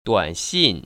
[duănxìn] 뚜안신